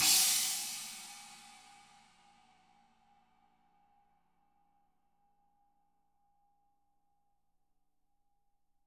Index of /90_sSampleCDs/ILIO - Double Platinum Drums 1/CD4/Partition F/CHINA CRASHD
CHINA20 FD-L.wav